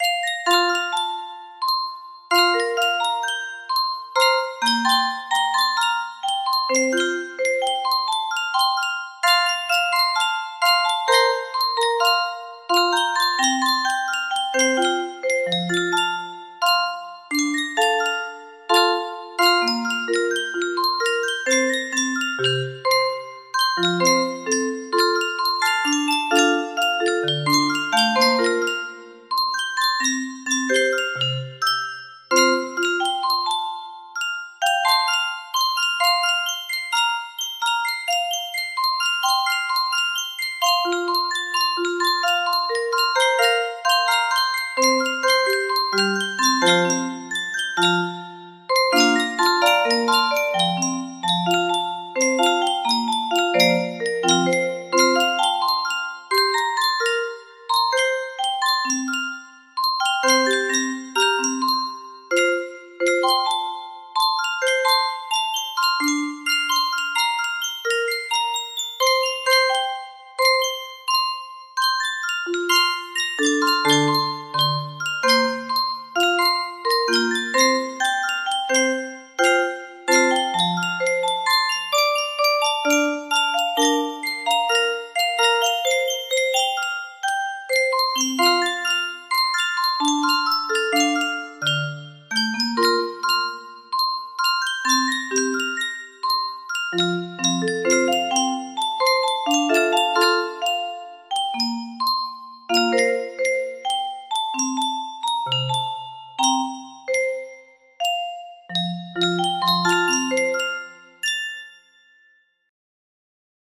Full range 60
proper tempo no reds